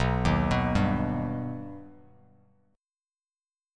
B+ Chord
Listen to B+ strummed